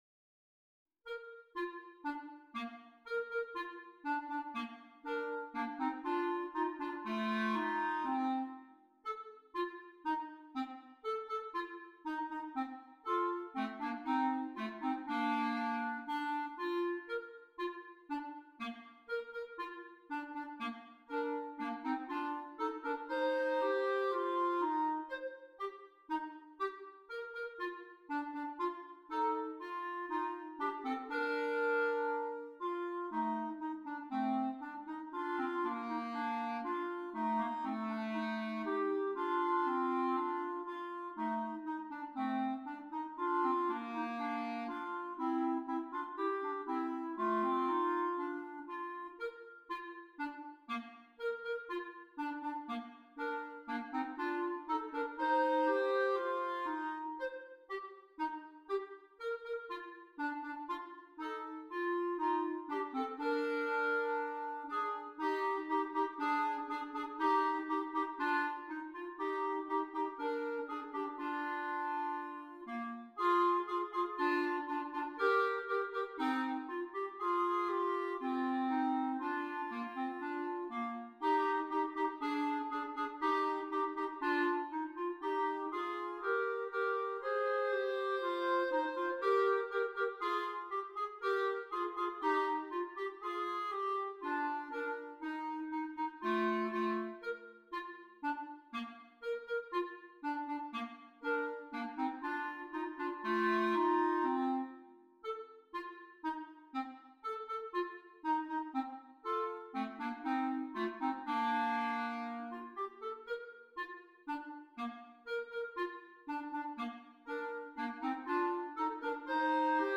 2 Clarinets
Difficulty: Easy Order Code